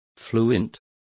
Complete with pronunciation of the translation of fluent.